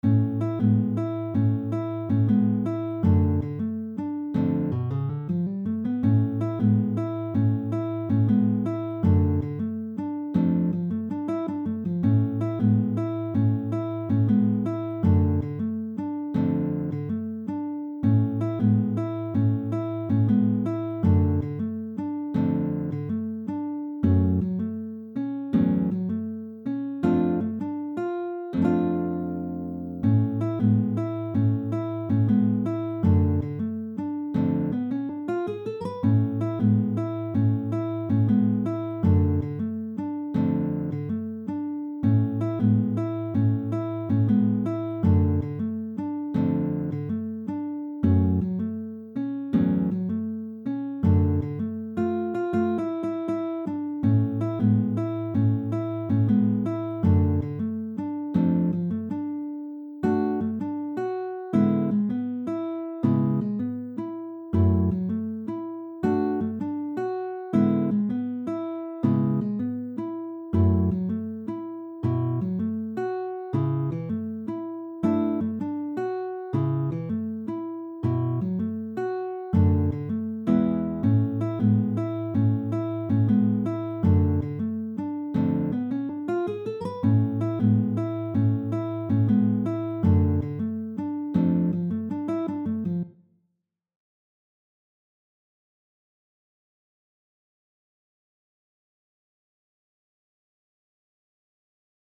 Comprendere meglio la musica e suonare meglio la chitarra
5 pagine accompagnamento complete Pdf tab